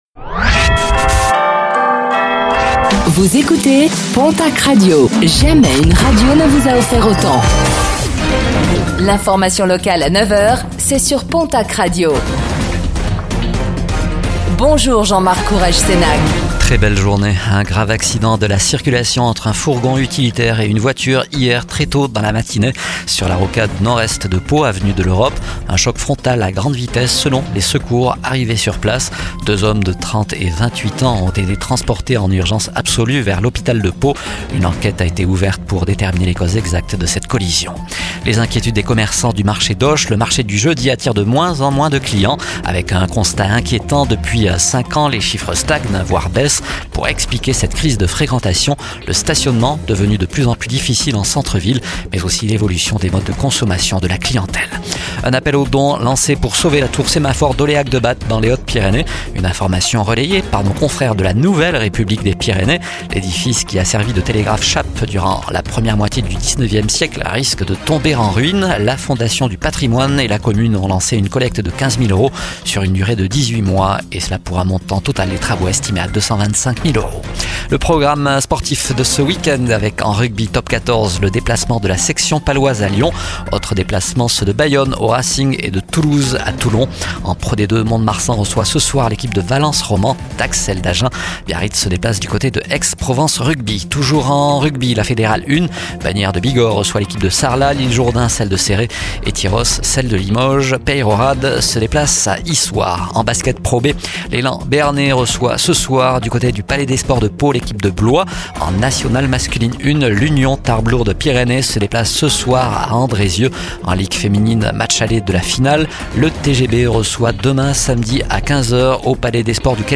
Infos | Vendredi 09 mai 2025